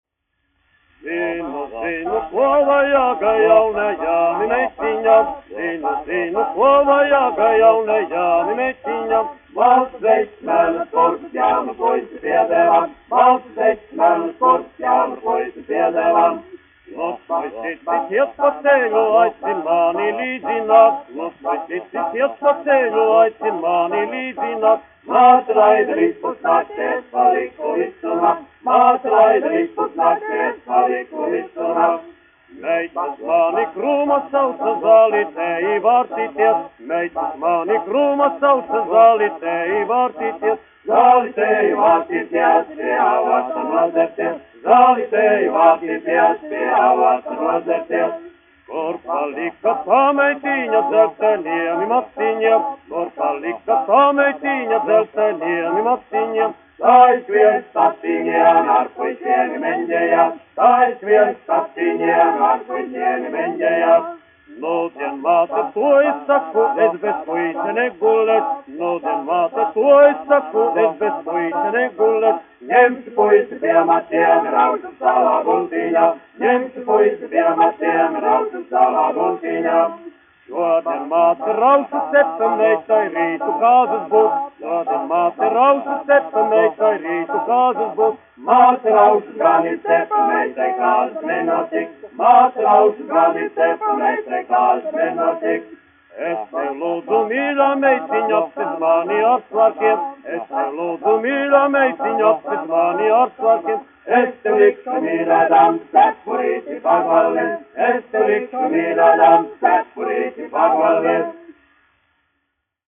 1 skpl. : analogs, 78 apgr/min, mono ; 25 cm
Latviešu tautasdziesmu aranžējumi
Vokālie seksteti
Polkas
Latvijas vēsturiskie šellaka skaņuplašu ieraksti (Kolekcija)